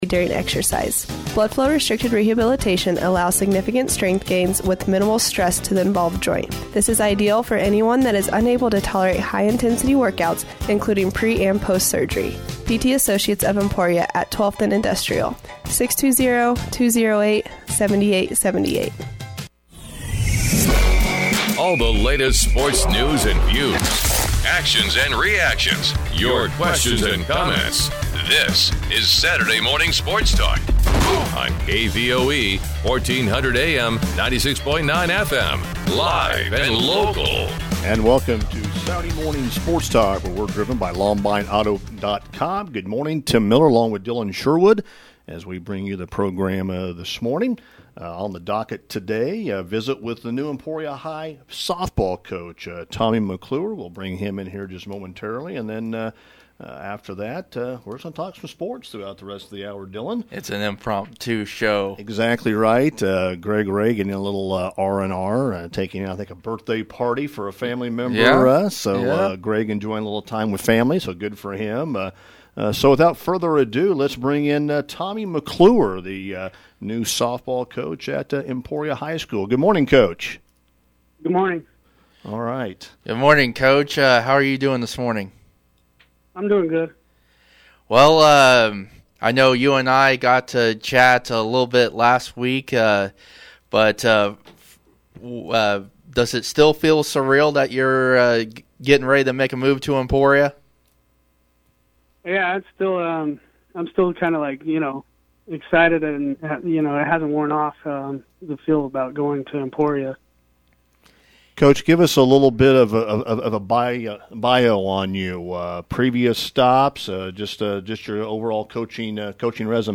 Saturday Morning Sports Talk